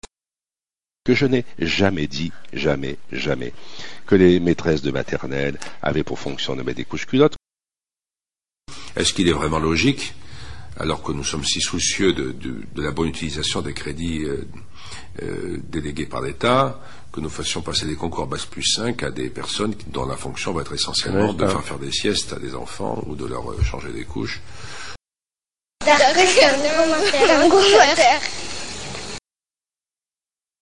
Le vendredi 21 novembre 2008 sur france-inter Monsieur Xavier Darcos affirme n'avoir jamais dit "Que les maîtresses de maternelle avaient pour fonction de mettre des couches-culottes".